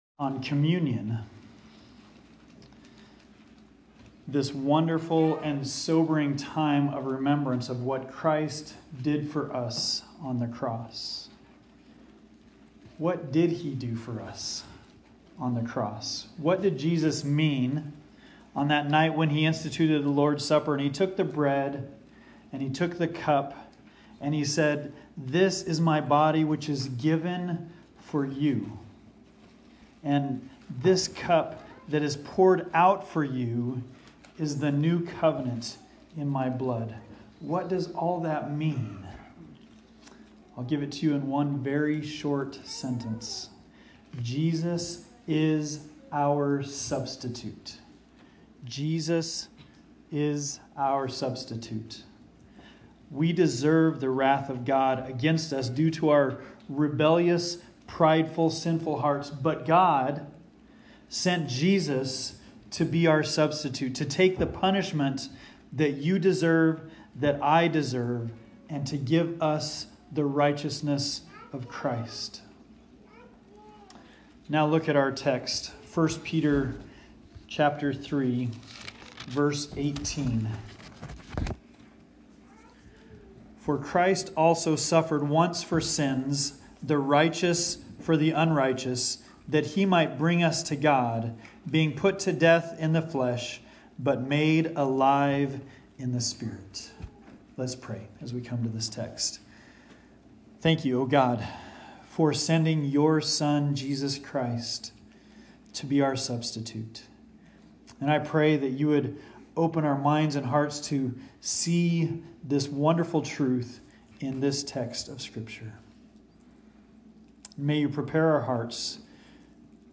Communion Meditation: Substitution